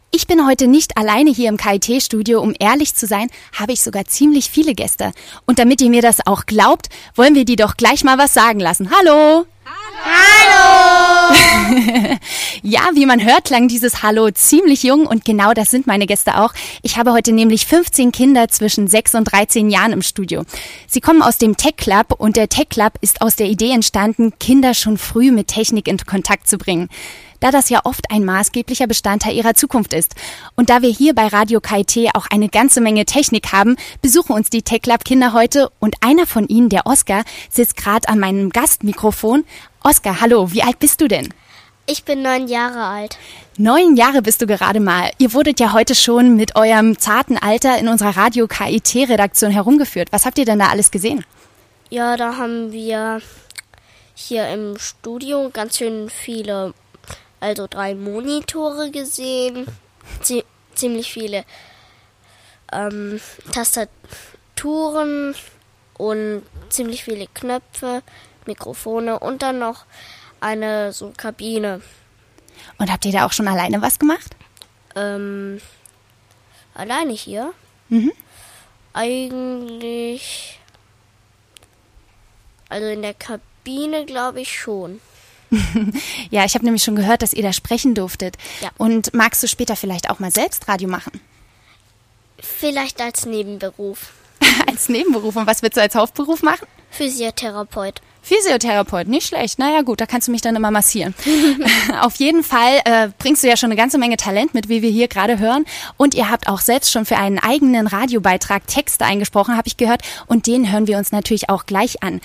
Club der Technikbegeisterten - Vorstellung im Studio : Beitrag bei Radio KIT am 16.06.2011